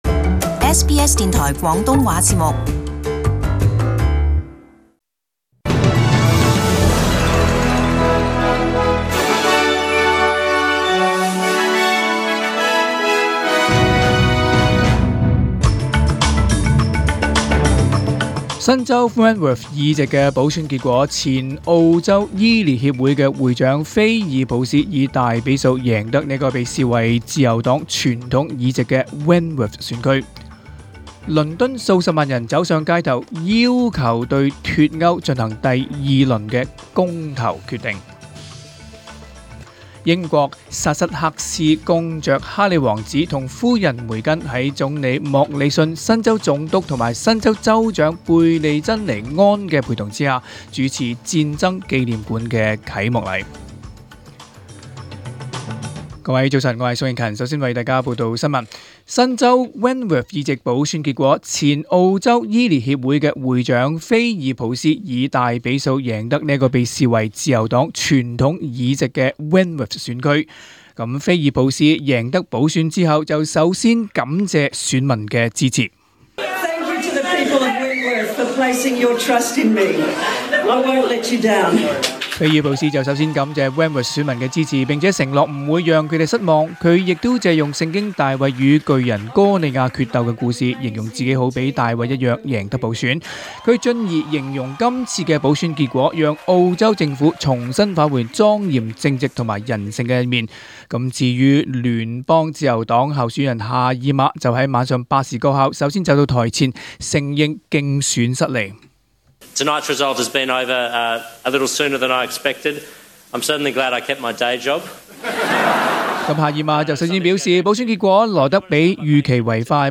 Cantonese 10 am news Source: SBS